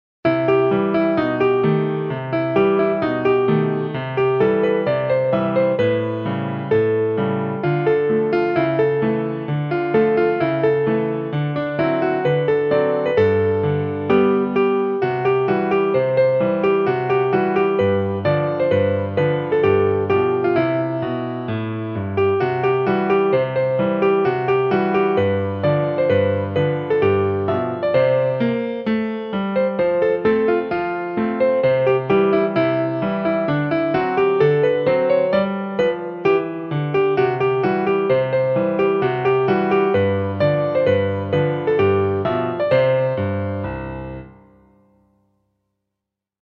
Pianist - Arrangeur
Pianist
(vereinfachte Version)